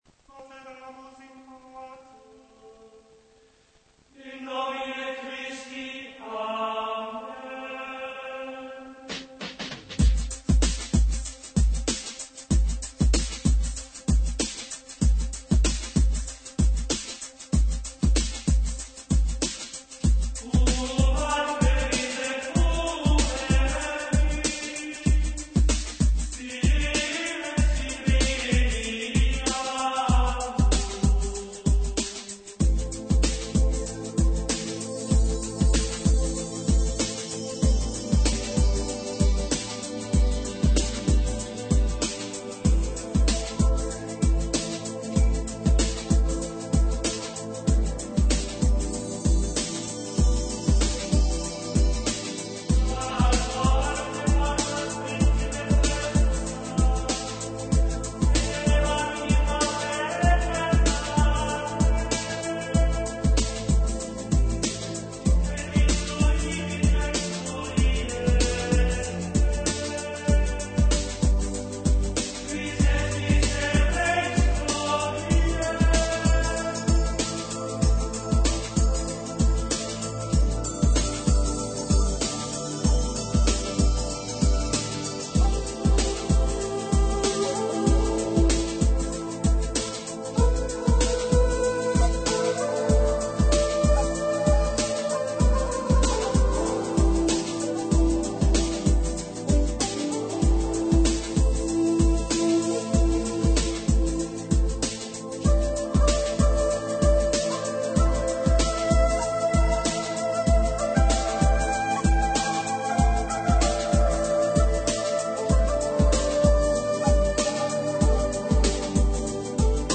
Un mix 100% generation 90 !